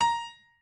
pianoadrib1_6.ogg